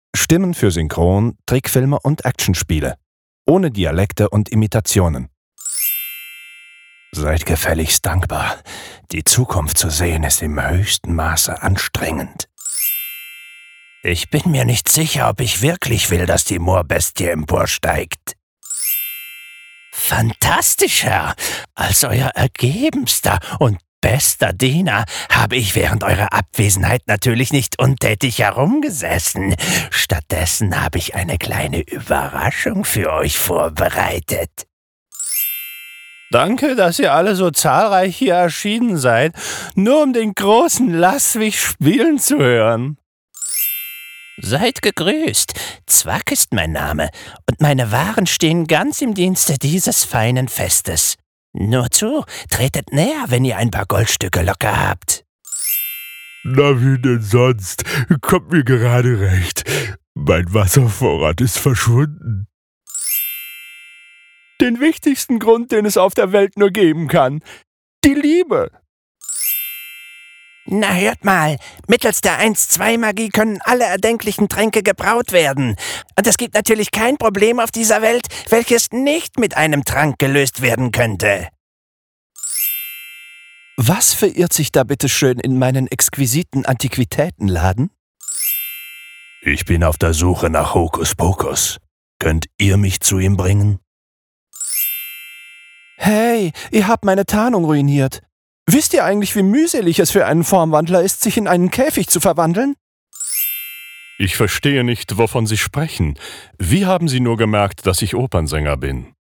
Sprecher für Synchron, Trickfilm, Spiele:
sprecher-synchrontrickfilmspiele-ohne-dialekteimitationen.mp3